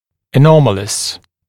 [ə’nɔmələs][э’номэлэс]аномальный, ненормальный, неправильный